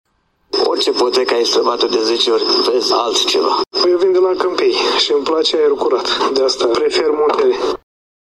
Turiștii spun că sosesc cu drag la Brașov pentru că peisajele sunt pitorești, se bucură de aerul curat de munte, dar și de obiectivele turistice variate: